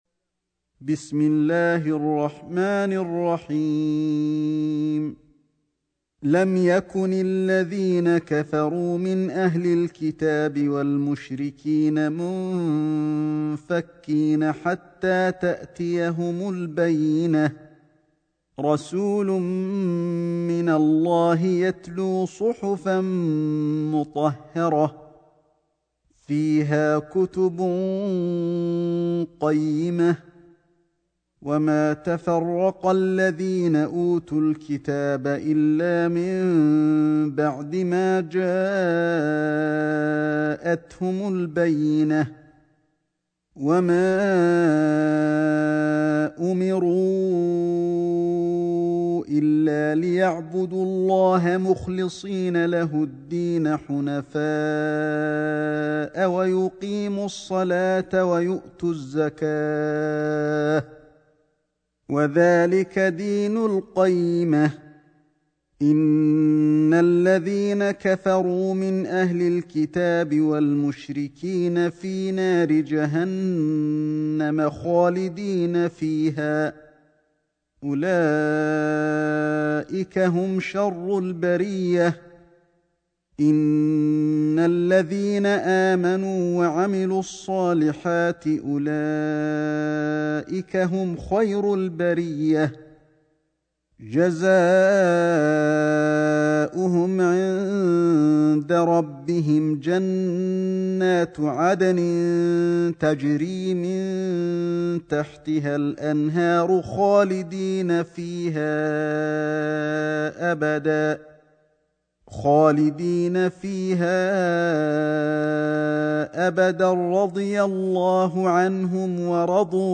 سورة البينة > مصحف الشيخ علي الحذيفي ( رواية شعبة عن عاصم ) > المصحف - تلاوات الحرمين